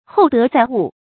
厚德載物 注音： ㄏㄡˋ ㄉㄜˊ ㄗㄞˋ ㄨˋ 讀音讀法： 意思解釋： 舊指道德高尚者能承擔重大任務。